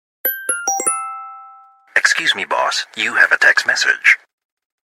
Genre: Nada notifikasi